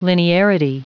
Prononciation du mot linearity en anglais (fichier audio)
Prononciation du mot : linearity